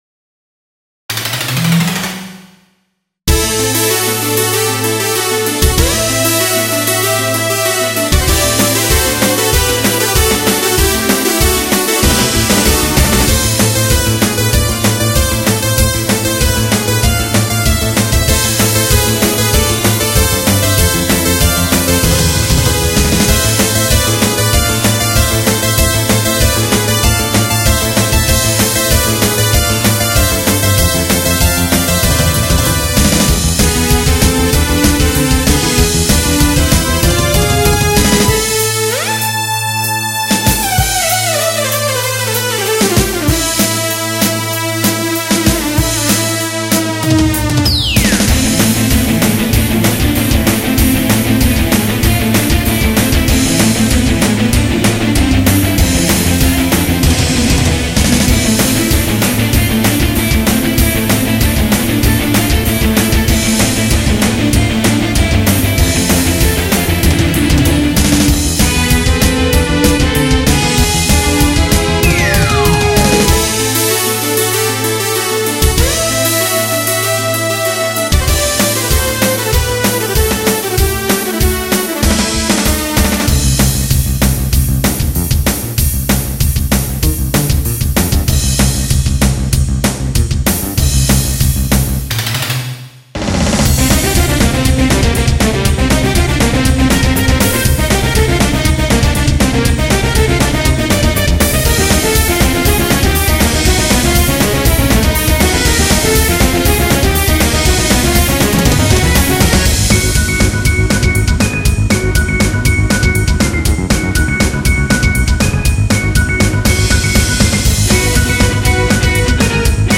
メドレーっぽい劣化コピーです。